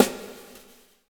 SND DRUMAD-L.wav